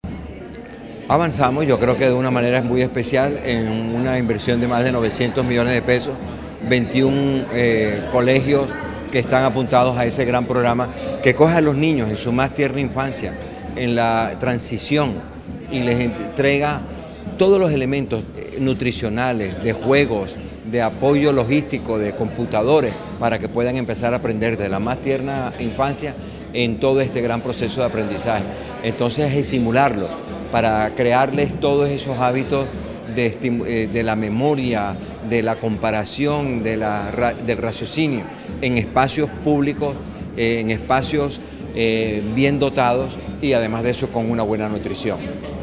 El Gobernador Eduardo Verano y el Viceministro de Preescolar Básica y Media, Víctor Saavedra asistieron al lanzamiento del programa en el auditorio de la Escuela Normal de Manatí, con presencia de estudiantes de transición, docentes, padres de familia y directivos docentes.
Gobernador-Eduardo-Verano2c-Transición-es-una-nota.mp3